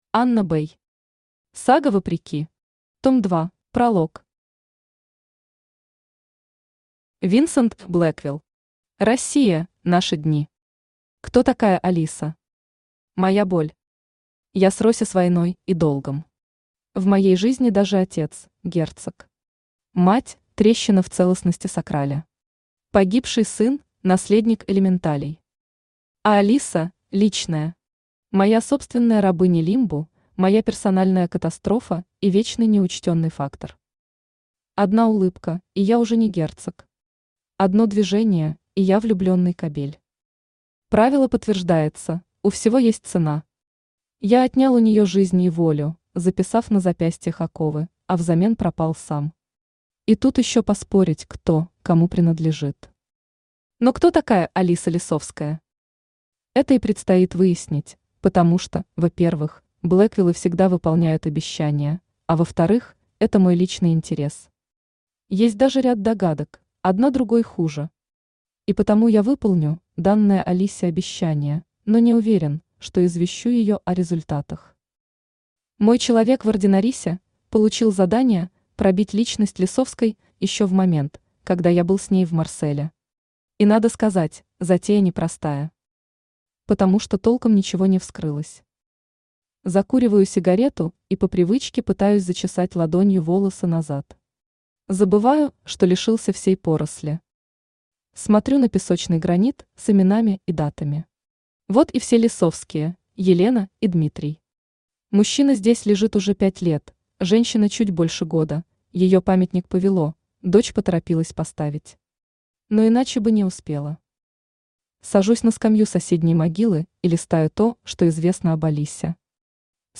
Аудиокнига Сага Вопреки. Том 2 | Библиотека аудиокниг
Том 2 Автор Анна Бэй Читает аудиокнигу Авточтец ЛитРес.